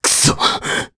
Crow-Vox_Damage_jp_01.wav